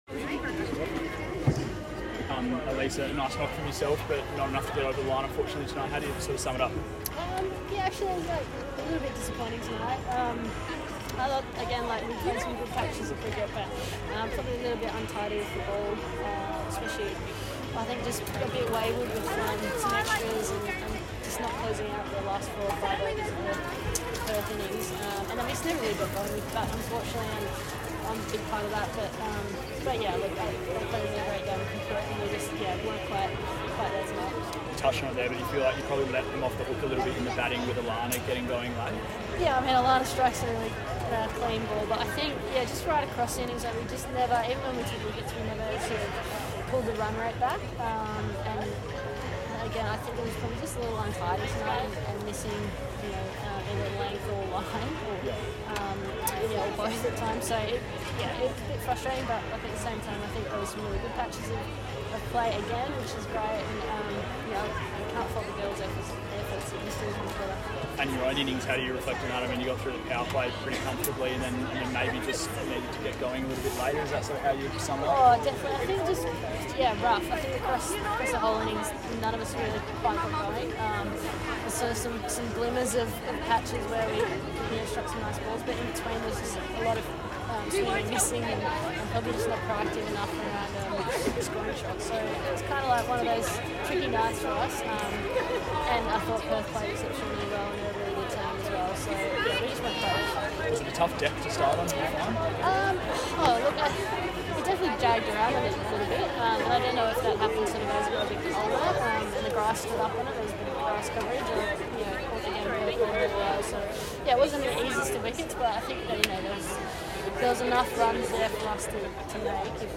Sixers captain Ellyse Perry speaking post 36-run loss to the Scorchers